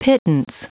Prononciation du mot pittance en anglais (fichier audio)
Prononciation du mot : pittance